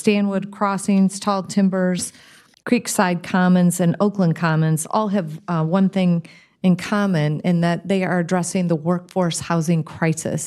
Mayor Patricia Randall says she has been to four groundbreakings on new residential projects recently.